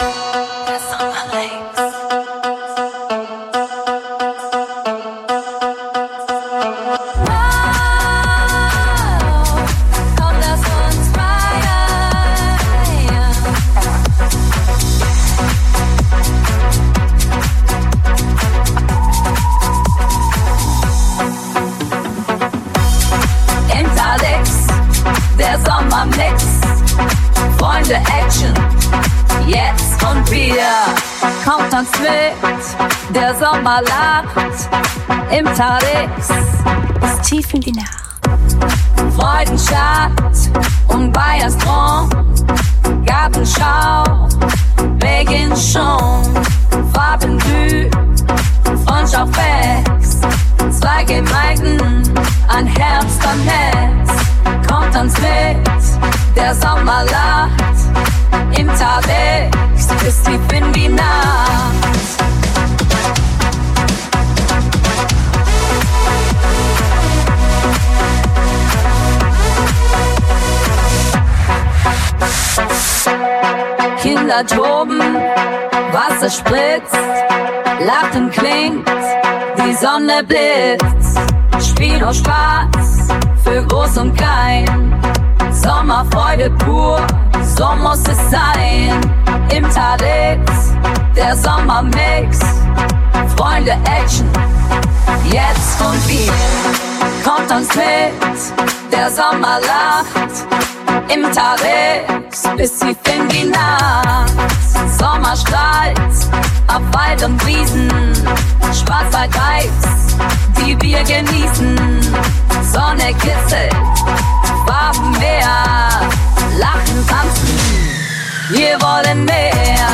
Der Sommerhit der Gartenschau
Mitreißender Sound